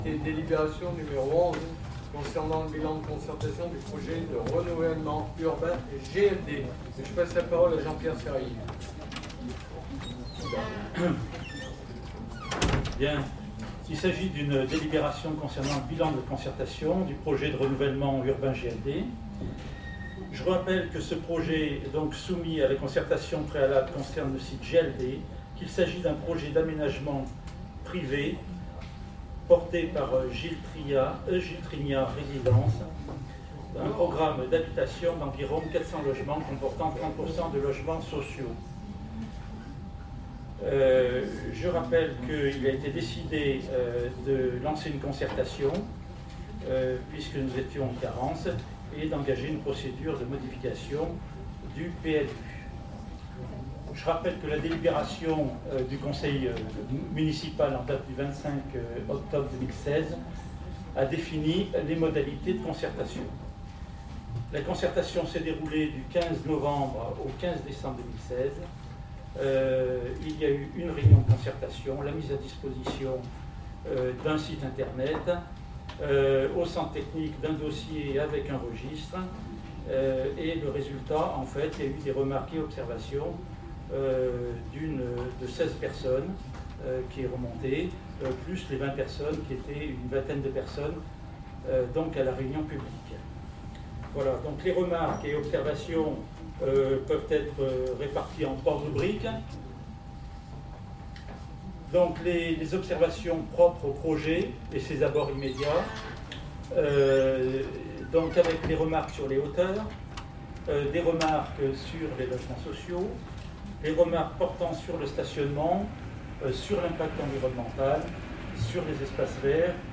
Les élus du groupe d’opposition ont exprimé leurs réserves et se sont abstenus du vote. Vous pouvez lire le compte rendu de ce Conseil dans le blog du groupe APS et aussi écouter l’enregistrement audio avec l’intégralité des discussions lors du Conseil municipal :